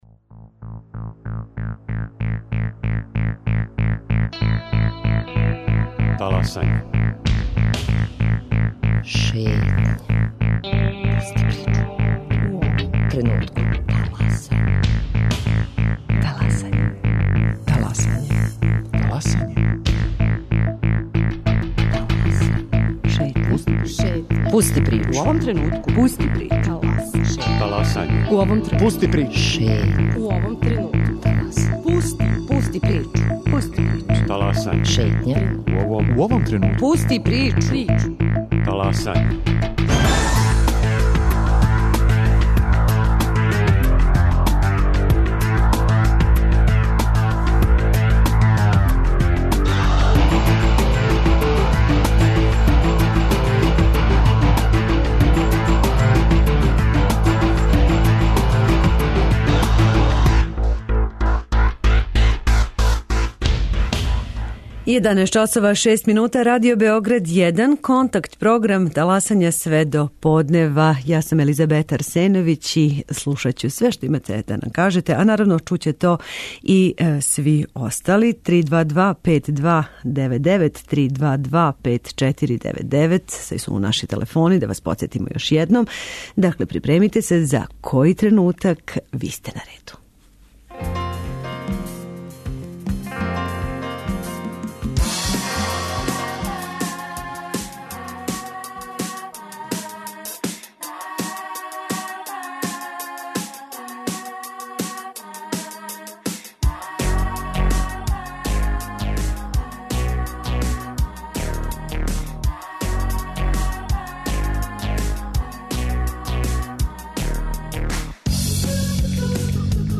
Контакт програм Радио Београда 1! Ваше опаске, примедбе, покуде и похвале реците јавно, а ми ћемо слушати пажљиво!